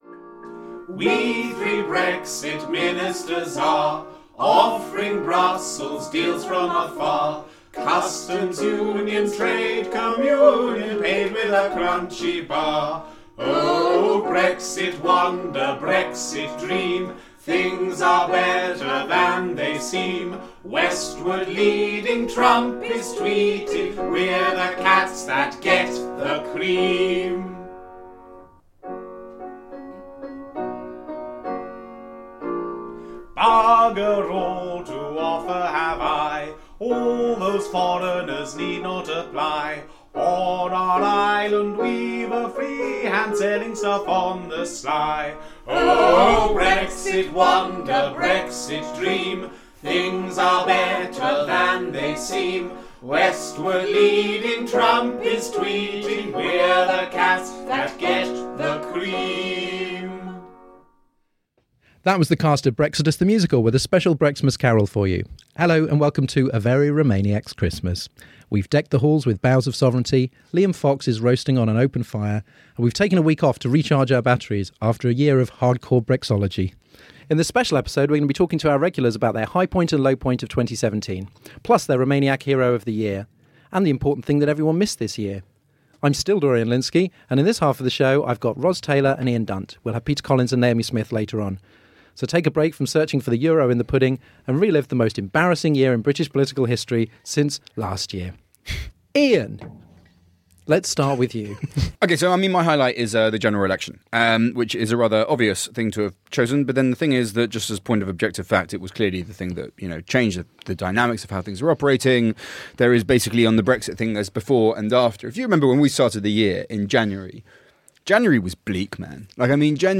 Plus we’ve got exclusive Brexmas Carols from the magnificent cast of Brexodus: The Musical.
WARNING - This show contains adult language as well as seditious conversation.